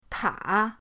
ta3.mp3